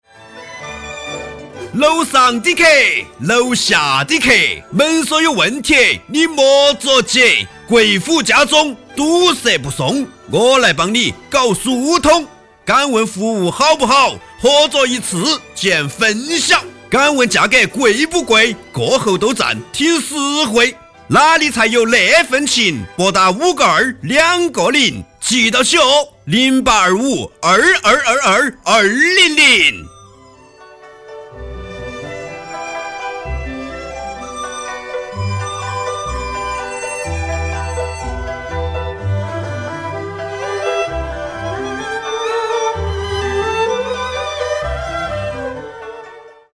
方言四川话
积极向上